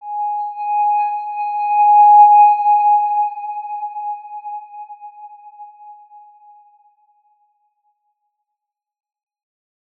X_Windwistle-G#4-pp.wav